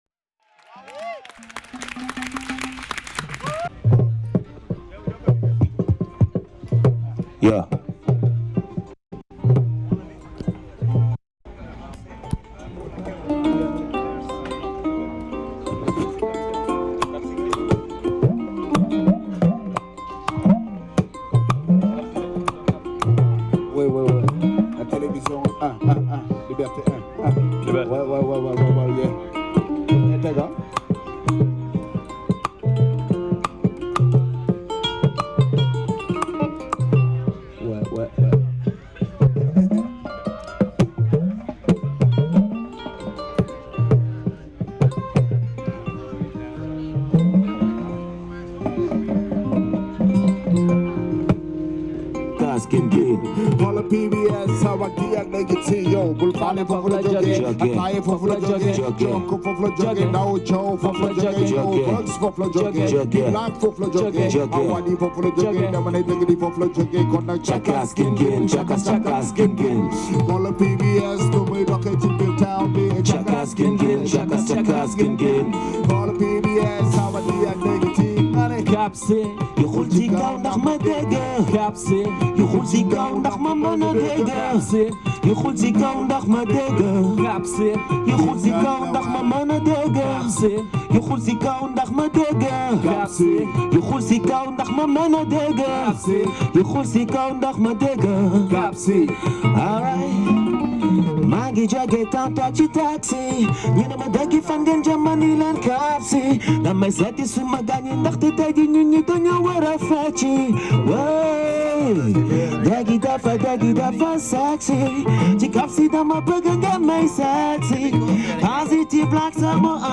A crew of rappers and musicians from Dakar, Senegal.
this is a fully live uncut, acoustic version
As performed live on a tiny stage under a huge tree.